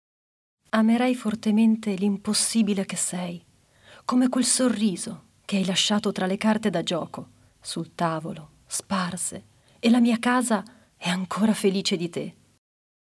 Dall’audiolibro Komorebi